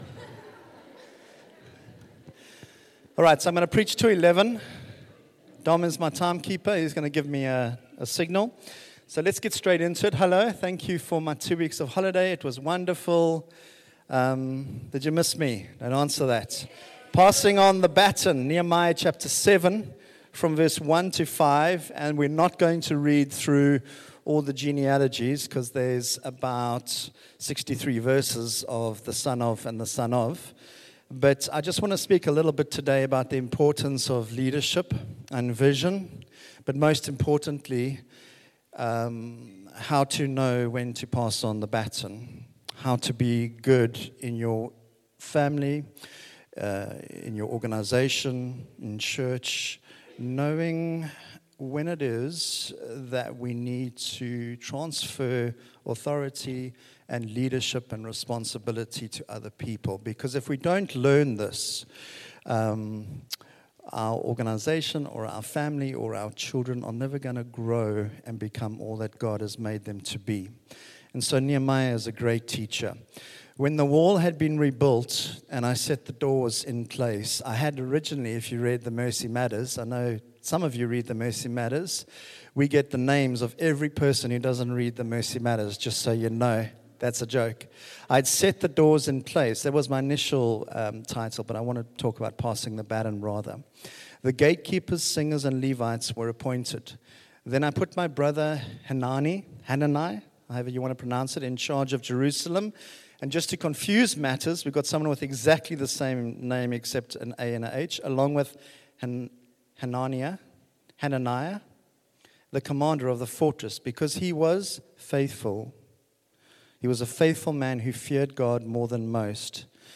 Sunday Service – 25 August
Sermons